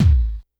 Kick_42.wav